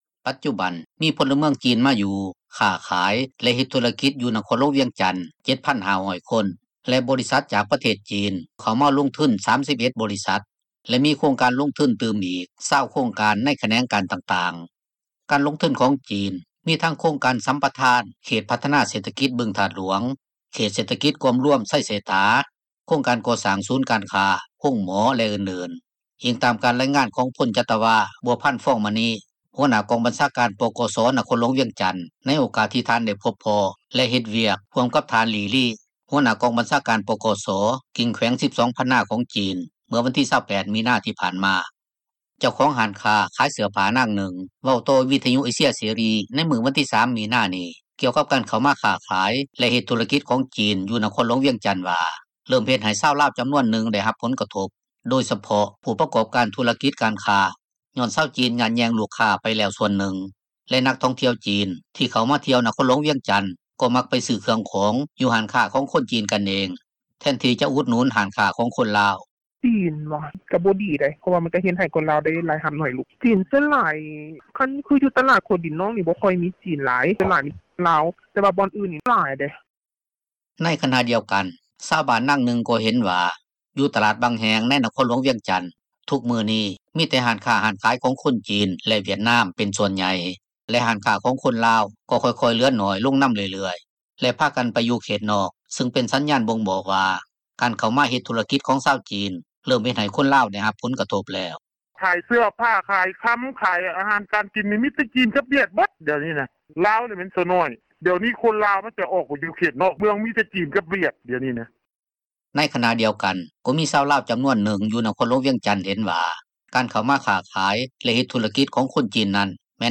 ເຈົ້າຂອງຮ້ານຄ້າຂາຍເສື້ອຜ້າ ນາງນຶ່ງ ເວົ້າຕໍ່ວິທຍຸເອເຊັຽເສຣີ ໃນມື້ວັນທີ 3 ເມສານີ້ ກ່ຽວກັບການເຂົ້າມາຄ້າຂາຍ ແລະເຮັດທຸຣະກິຈ ຂອງຊາວຈີນ ຢູ່ນະຄອນ ຫລວງວຽງຈັນວ່າ ເຣີ່ມເຮັດໃຫ້ຊາວລາວຈໍານວນນຶ່ງ ໄດ້ຮັບຜົລກະທົບ ໂດຍສະເພາະຜູ້ປະກອບການ ທຸຣະກິຈຄ້າຂາຍ ຍ້ອນຊາວຈີນຍາດແຍ່ງລູກຄ້າໄປແລ້ວສ່ວນນຶ່ງ ແລະນັກທ່ອງທ່ຽວຈີນ ທີ່ເຂົ້າມາທ່ຽວນະຄອນຫລວງວຽງຈັນ ມັກໄປຊື້ເຄື່ອງຂອງຢູ່ຮ້ານຄ້າ ຂອງຄົນຈີນກັນເອງ ແທນທີ່ຈະອຸດໜູນຮ້ານຄ້າຂອງຄົນລາວ.